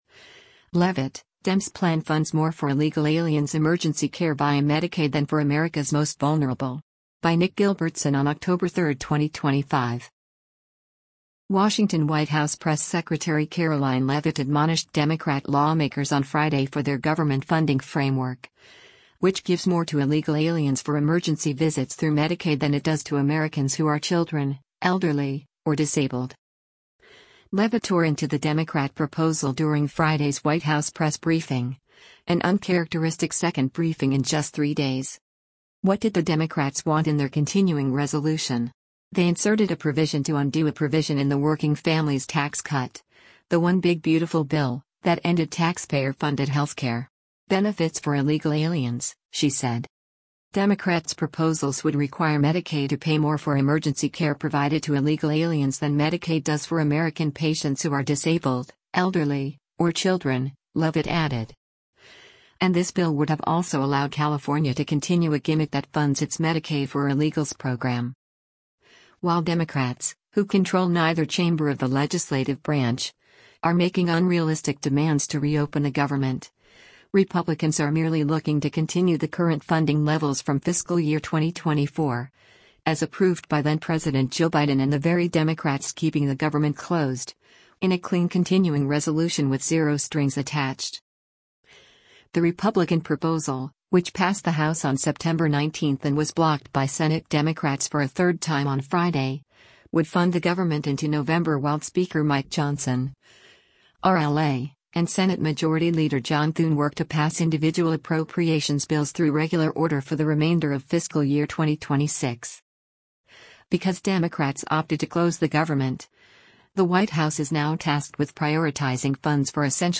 Leavitt tore into the Democrat proposal during Friday’s White House press briefing, an uncharacteristic second briefing in just three days.